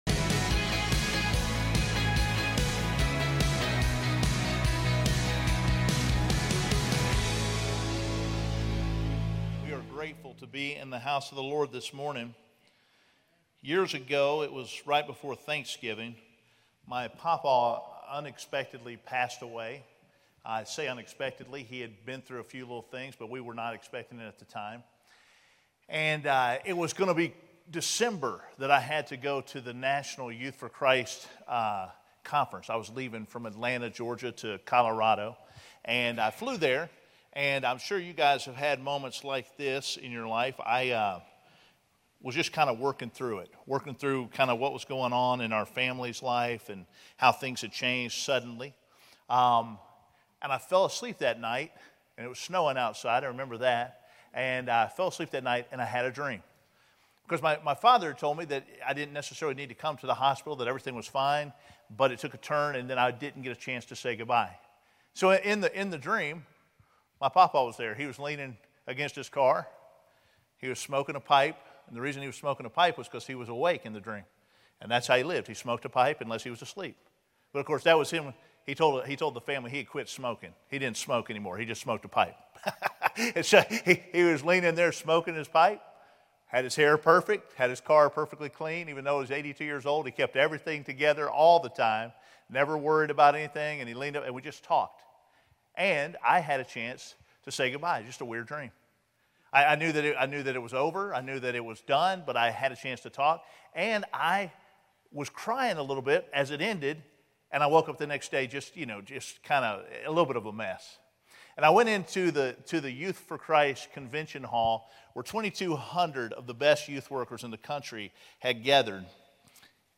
This is week five of our series, "What Would You Say?" In this sermon